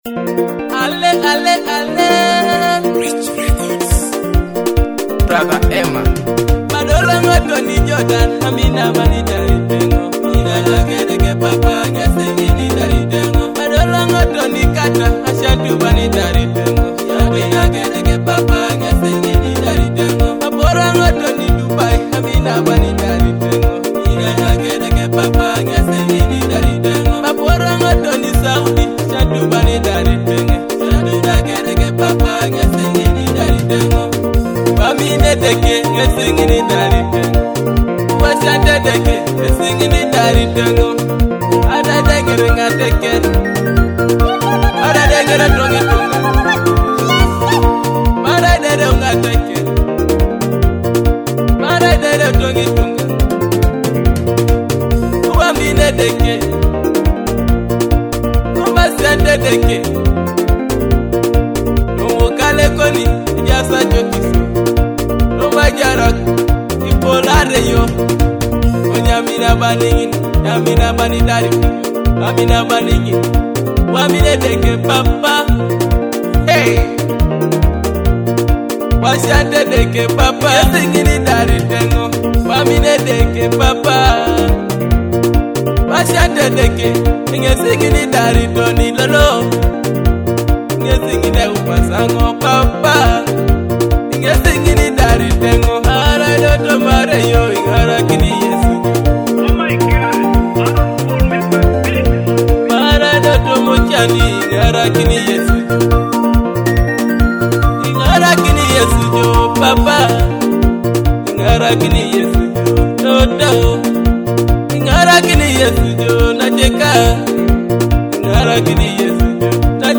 soulful delivery and infectious melody